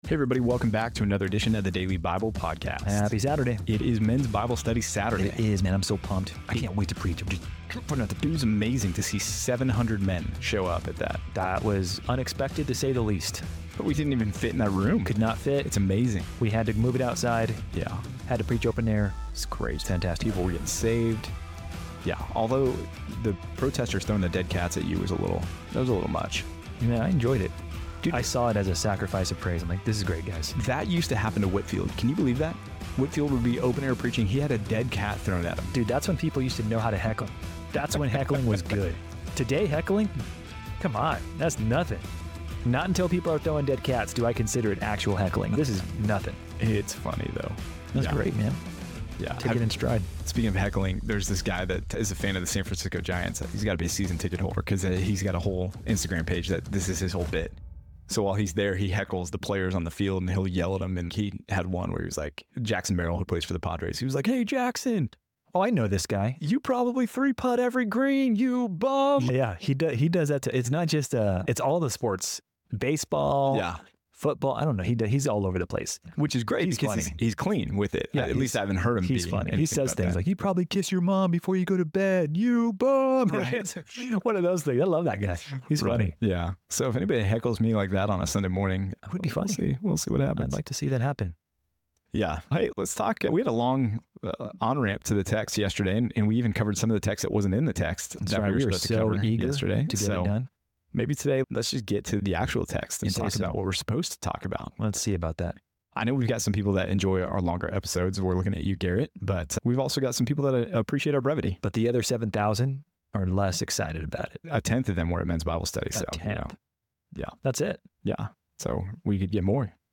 In this episode of the Daily Bible podcast, the hosts discuss the recent Men's Bible Study event, which saw an unexpectedly large turnout that required moving the gathering outside. They then delve into the story of Samson and Delilah from Judges 16, emphasizing Samson's vulnerability to sin and drawing parallels to personal challenges faced by believers. The hosts also cover Judges 17-18, highlighting the idolatry and moral decline during that period, and stressing the need for modern Christians to live with integrity and uphold biblical values.